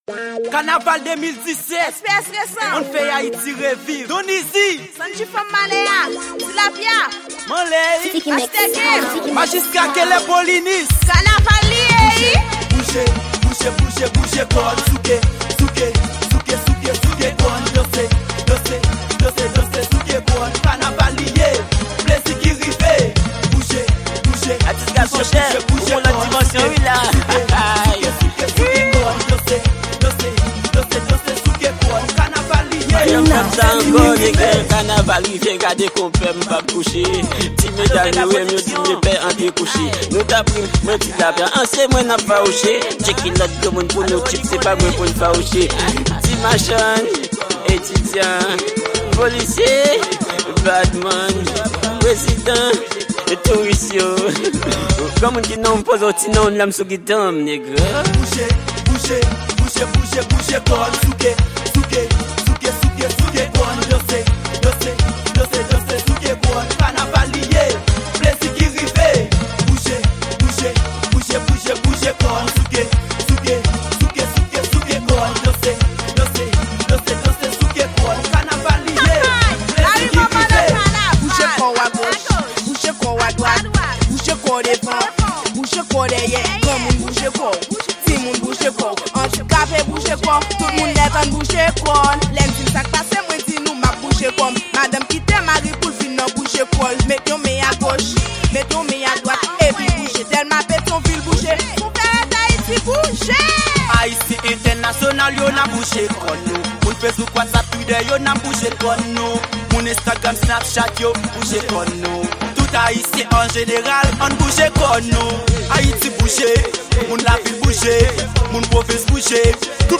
Genre: K-naval.